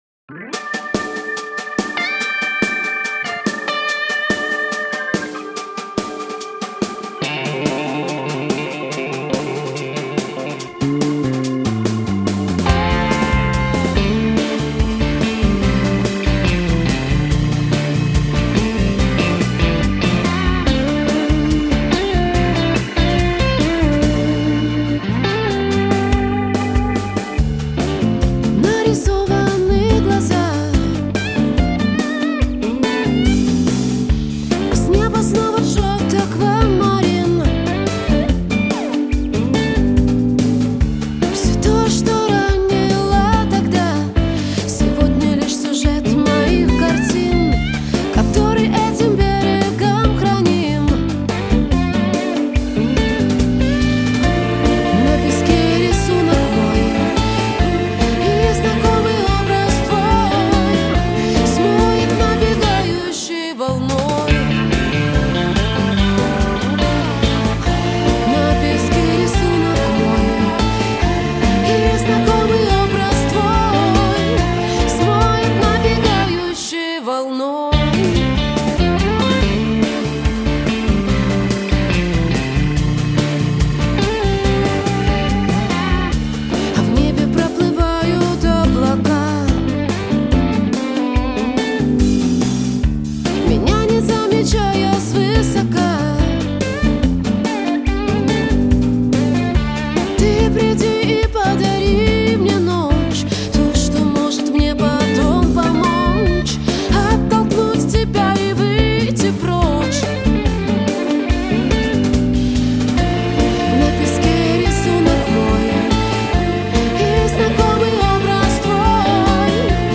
Pop-rock demo